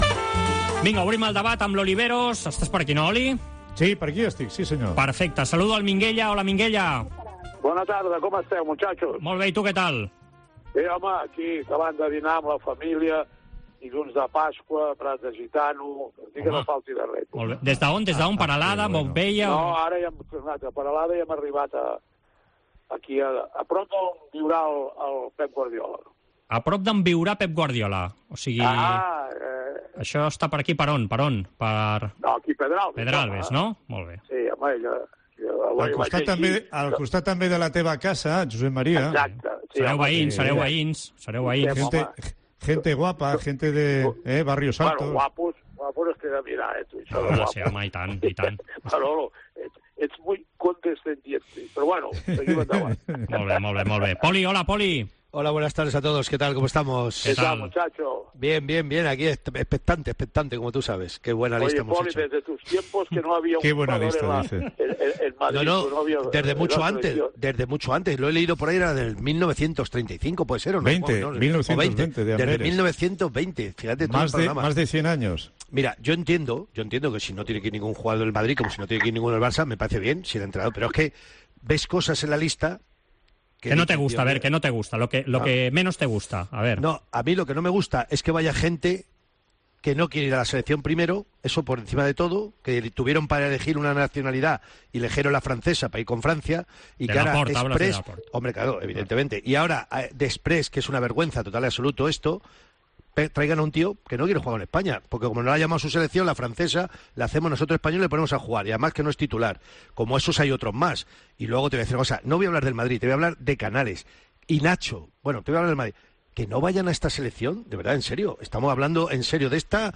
Escolta el 'Debat Esports COPE' amb Poli Rincón i Josep Maria Minguella analitzant la convocatòria del seleccionador espanyol per la cita d'aquest estiu.